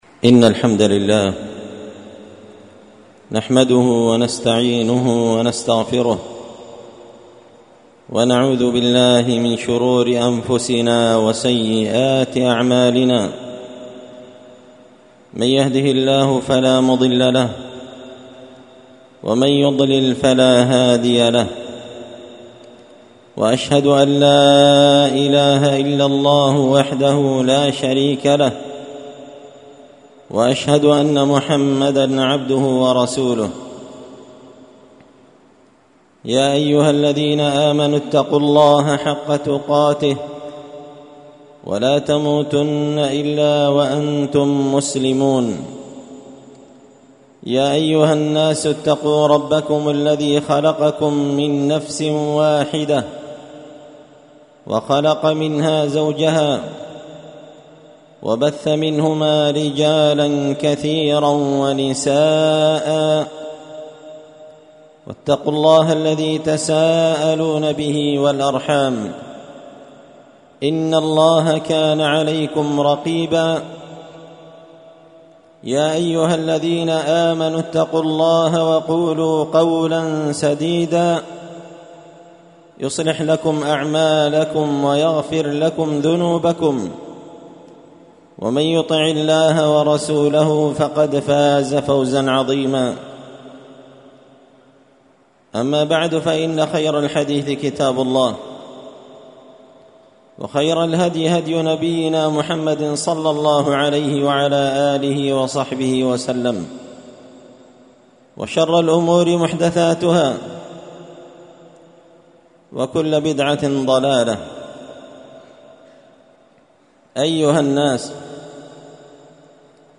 خطبة جمعة بعنوان:
ألقيت هذه الخطبة بدار الحـديـث السلفية بمـسجـد الفـرقـان قشن-المهرة-اليمن تحميل